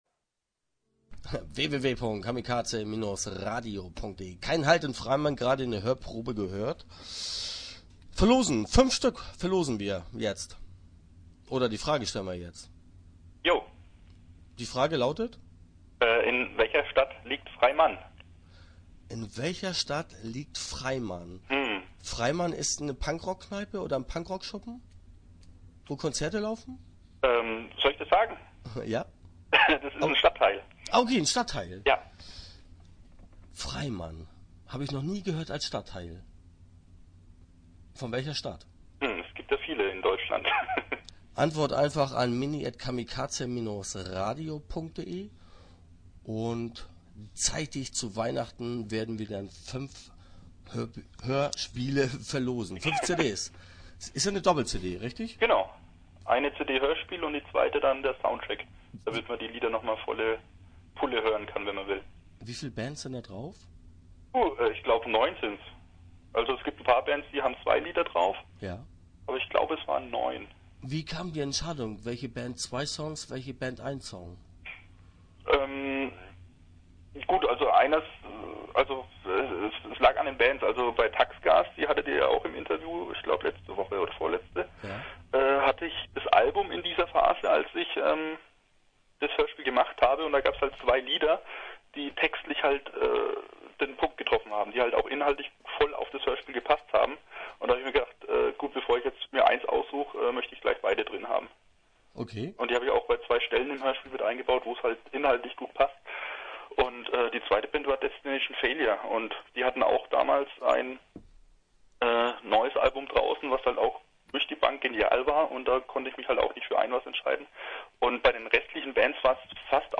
Start » Interviews » Kein Halt in Freimann